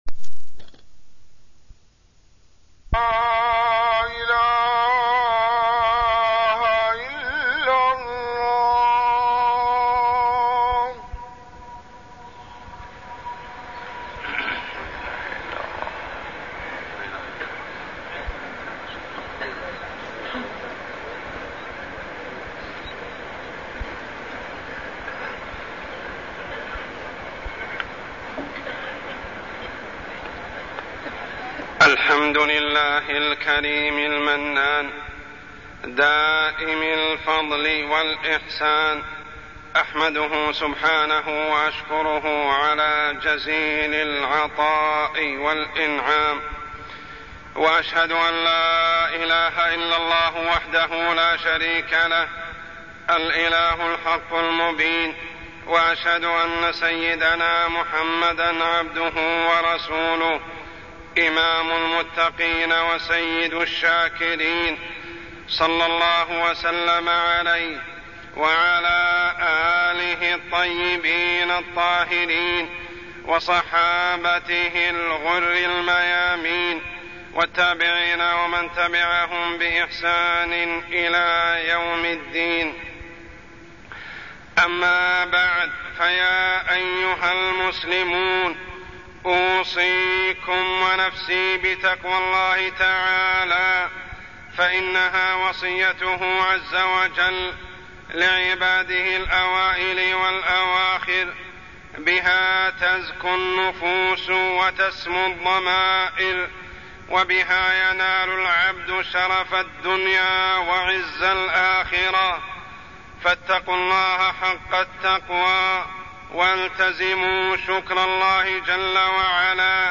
تاريخ النشر ٧ شوال ١٤٢٠ هـ المكان: المسجد الحرام الشيخ: عمر السبيل عمر السبيل مظاهر الجحود لنعم الله